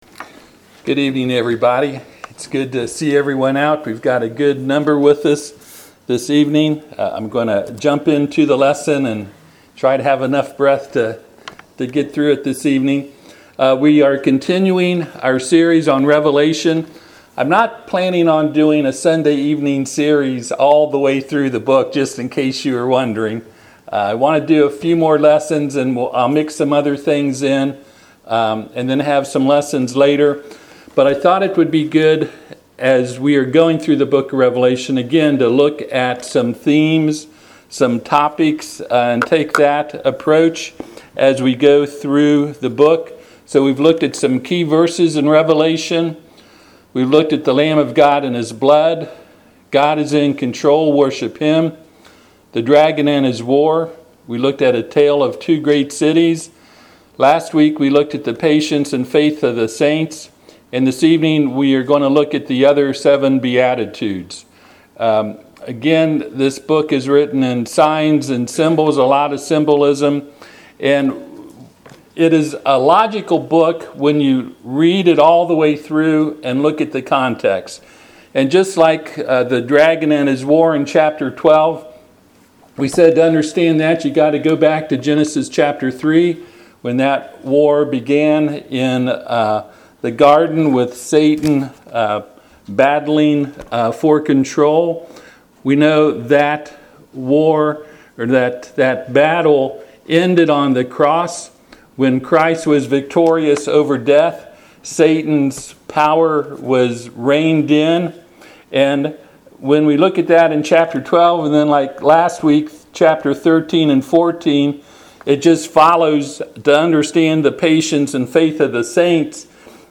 Service Type: Sunday PM Topics: apocalyptic , mark of beast , new earth , new heaven , prophecy